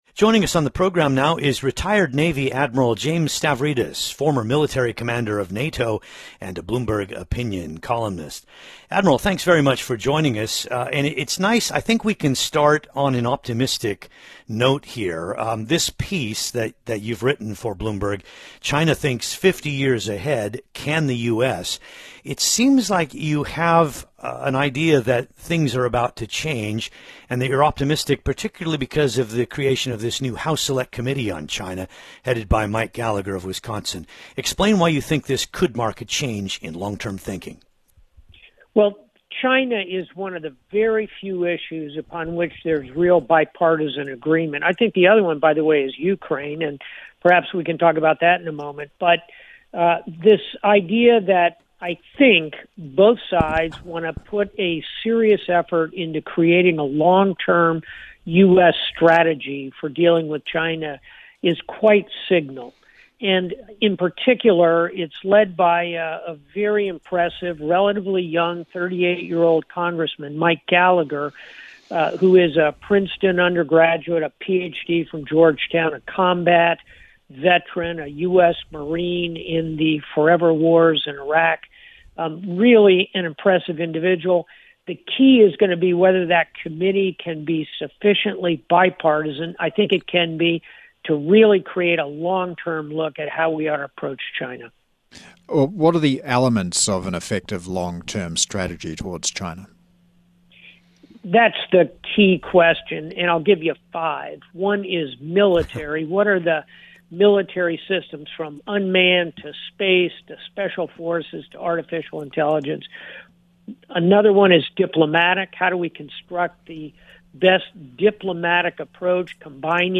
James Stavridis on China-US (Radio)